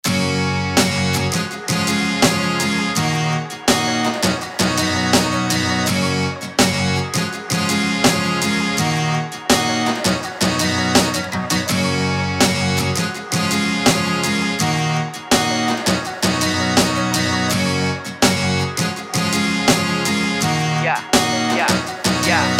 • Качество: 320, Stereo
гитара
без слов
инструментальные